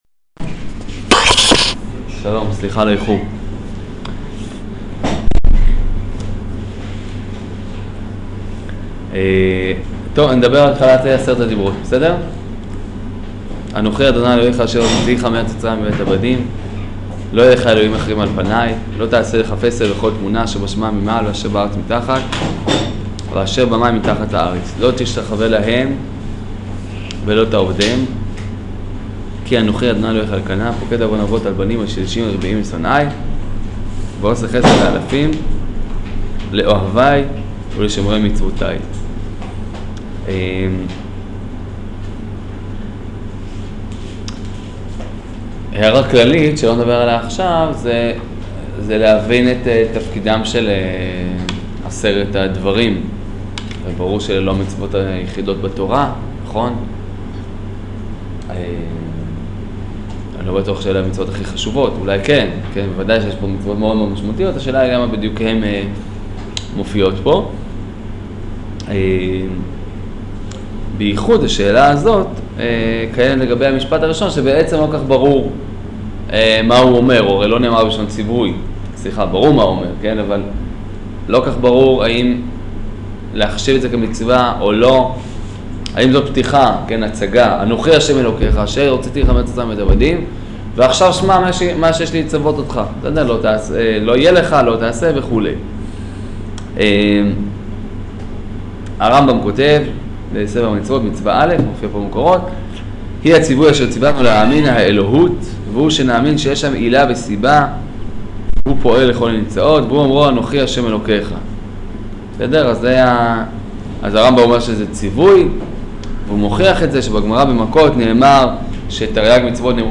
שיעור פרשת יתרו